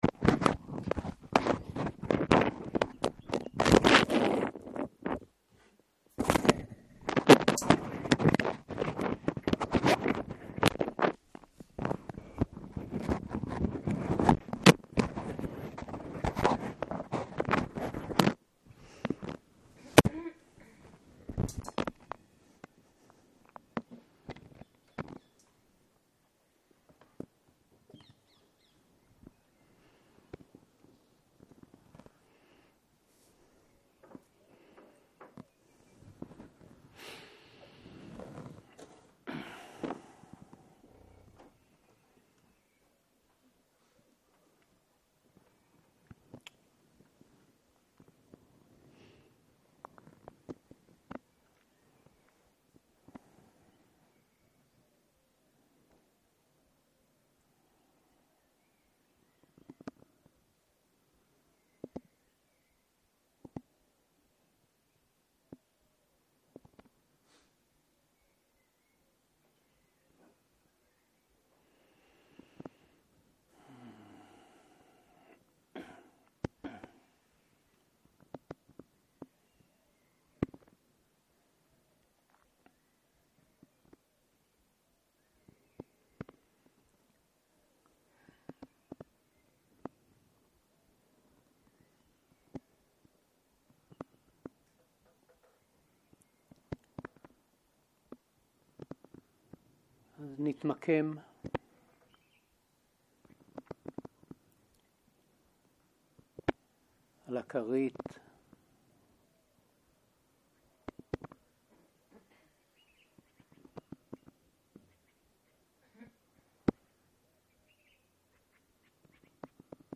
05.03.2023 - יום 3 - צהרים - מדיטציה מונחית - עירסול הנשימה - הקלטה 6